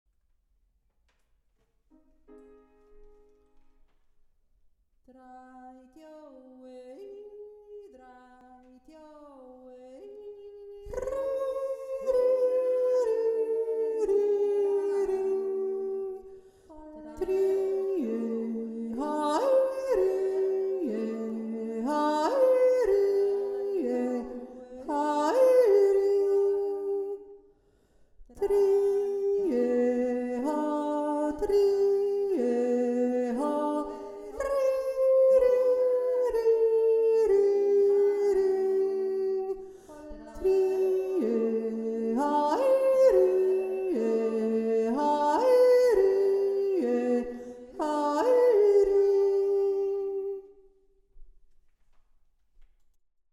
drei-djo-e-i-dritte-stimme.mp3